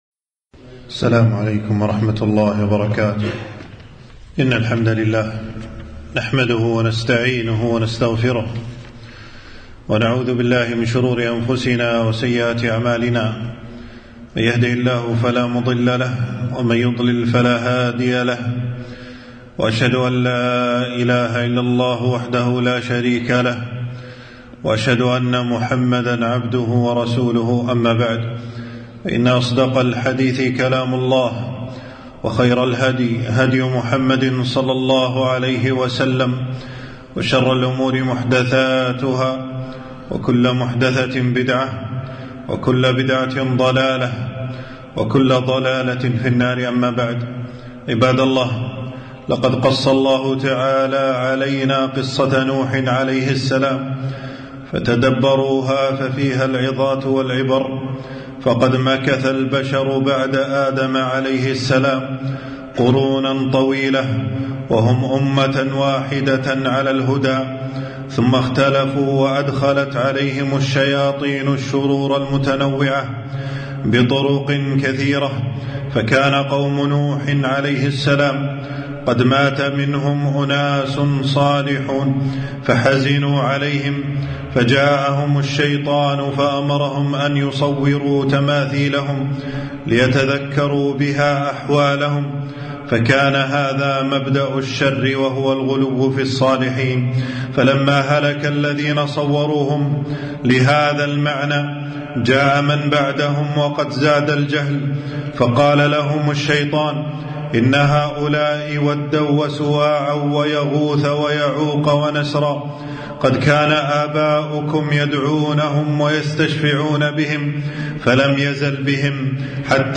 خطبة - مواعظ للمؤمنين من قصة نوح عليه السلام أول المرسلين